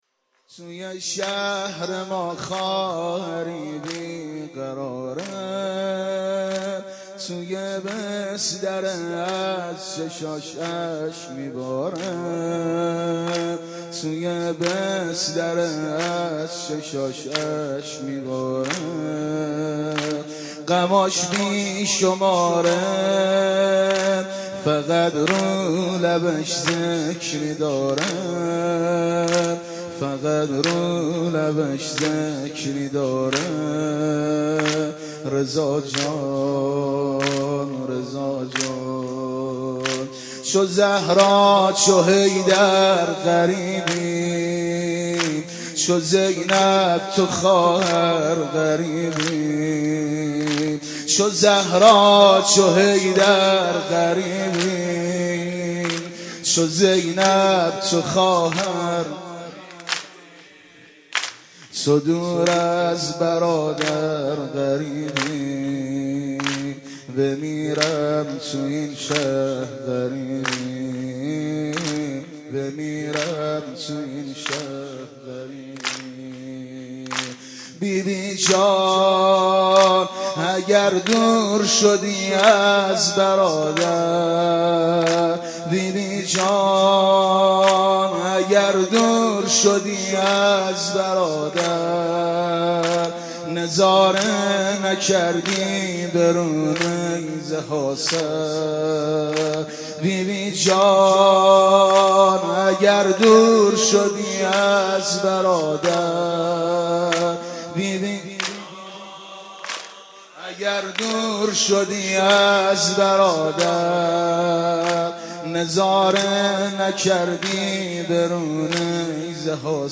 مراسم با شکوهی درحسینیه آیت الله العظمی مرعشی نجفی (ره)
فایل های صوتی مداحی را از طریق لینک های زیر دریافت نمایید:
زمینه.mp3